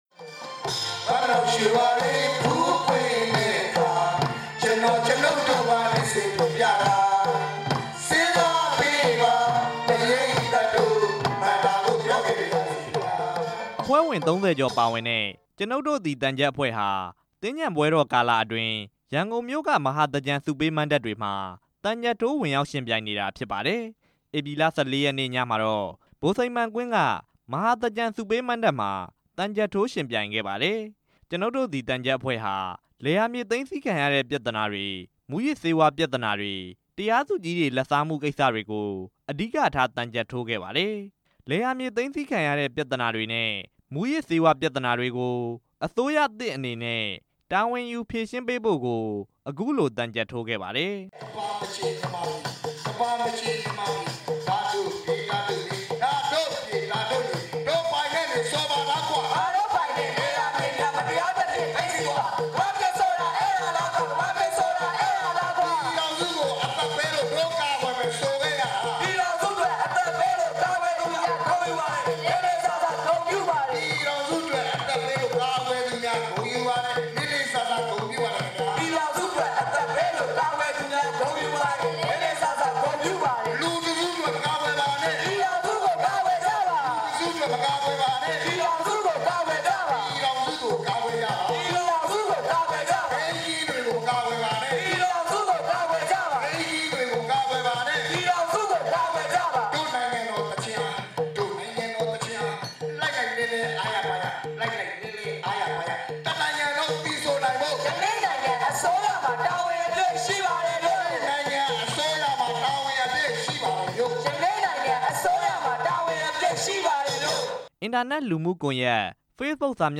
ရန်ကုန်တိုင်းဒေသကြီး ဗဟန်းမြို့နယ် ဗိုလ်စိန်မှန်အားကစားကွင်းမှာ အမျိုးသားဒီမိုကရေစီအဖွဲ့ချုပ် က ဦးဆောင်ပြုလုပ်တဲ့  မဟာသြင်္ကန်ဆုပေးမဏ္ဍပ်မှာ သြင်္ကန်ပထမအကြတ်နေ့ညက ကျွနု်ပ်တို့သည် သံချပ်အဖွဲ့ဝင်ရောက်ယှဉ်ပြိုင်ခဲ့ပါတယ်။